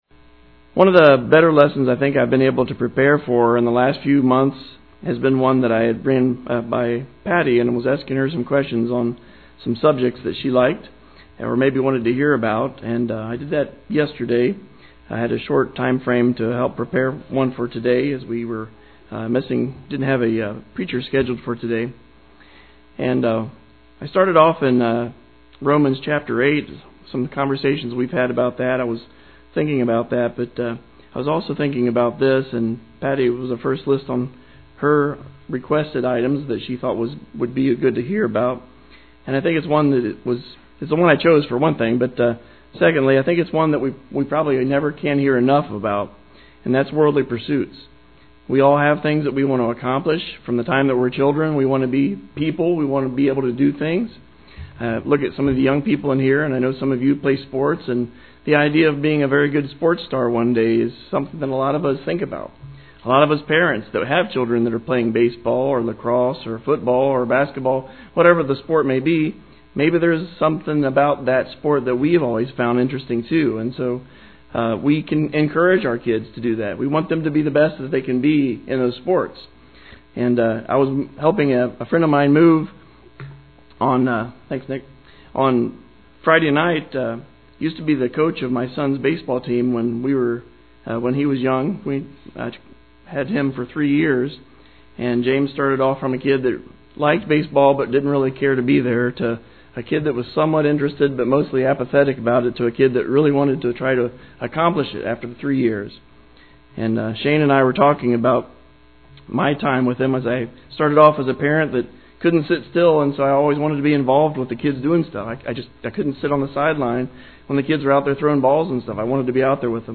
The lesson begins with what we tend to find “beautiful”… people, cars, houses, fame, power, or wealth.